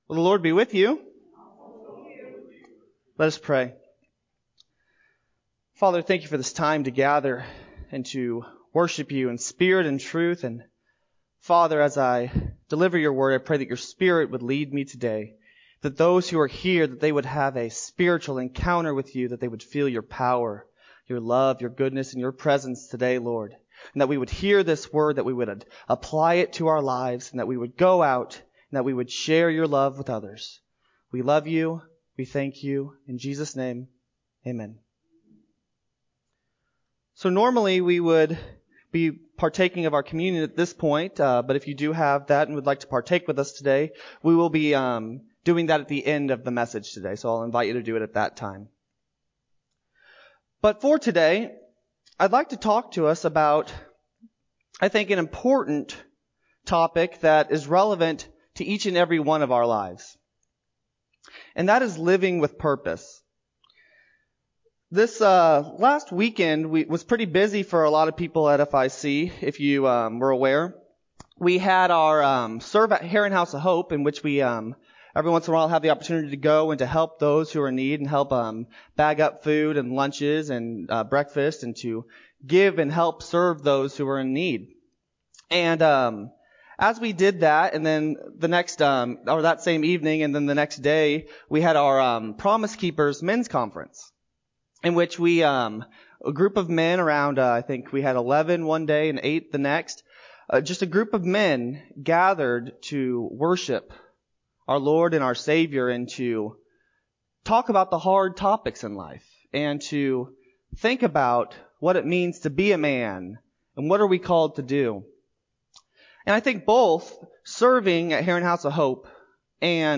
8-2-20-Sermon-CD.mp3